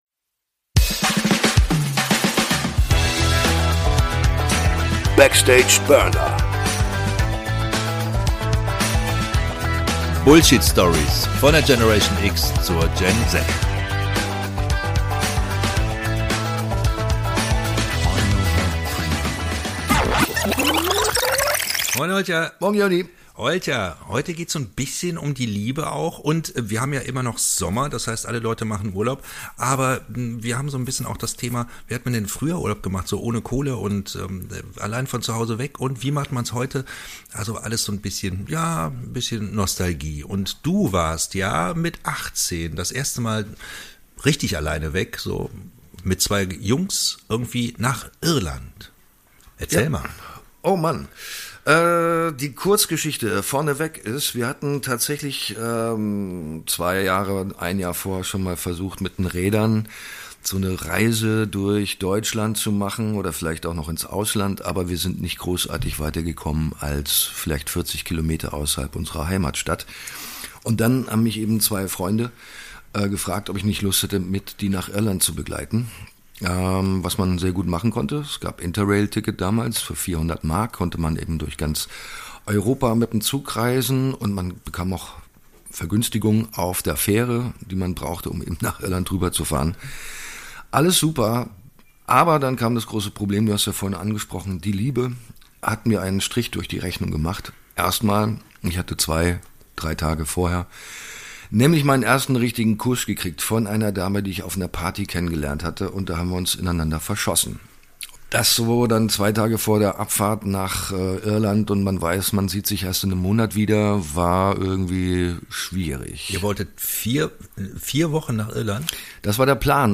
Eine angeregte Plauderei über emotionale und witzige Erlebnisse, zum Erinnern und Entspannen.